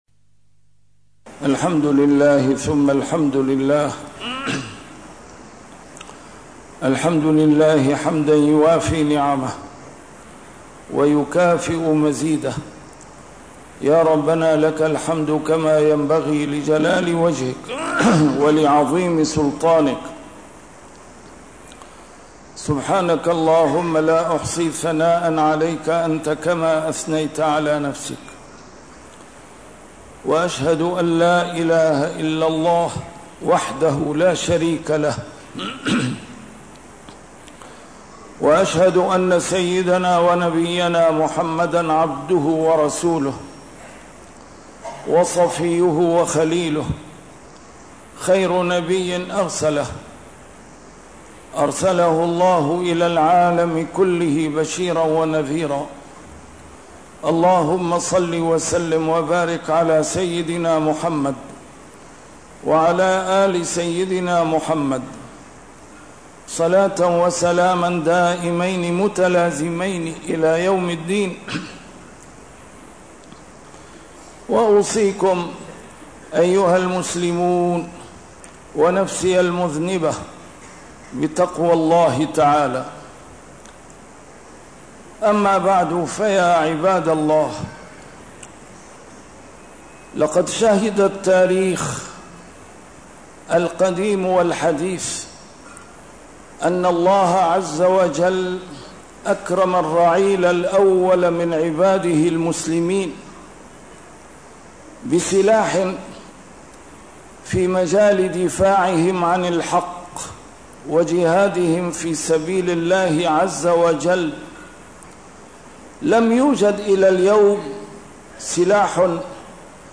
A MARTYR SCHOLAR: IMAM MUHAMMAD SAEED RAMADAN AL-BOUTI - الخطب - يا مسلمي العالم أين أنتم من سلاح الدعاء وصدق الالتجاء إلى الله؟‍!...